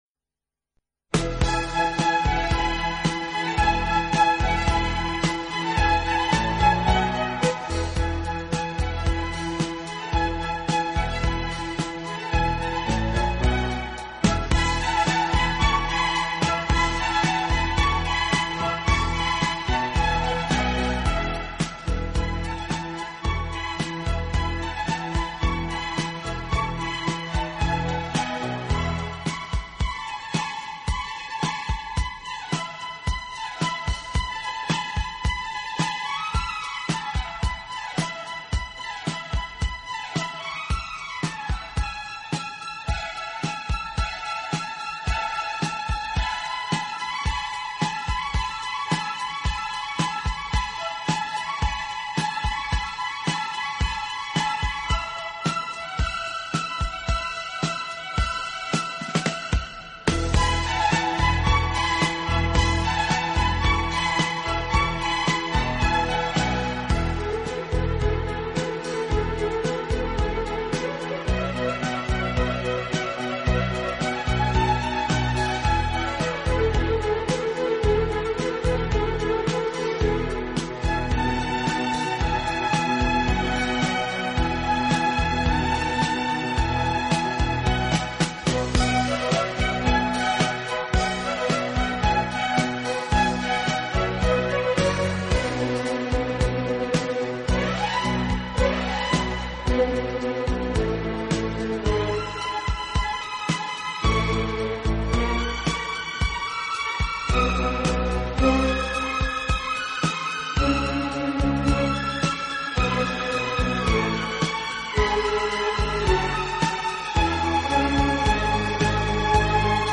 此外，这个乐队还配置了一支训练有素，和声优美的伴唱合唱队。
这张专辑运用了一些不常用的器乐，增加了神秘感和趣味感。